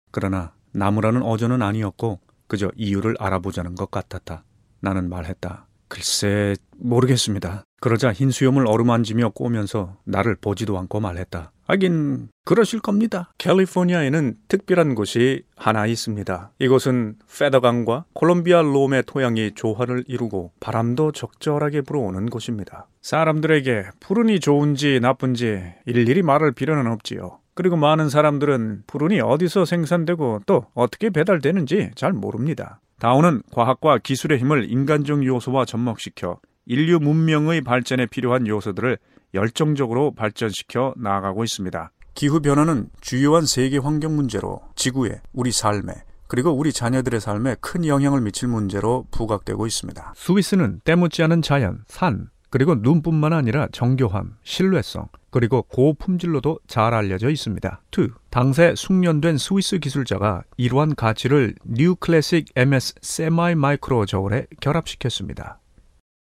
Calm, audio book, authoritive, funny, character voice
Sprechprobe: Industrie (Muttersprache):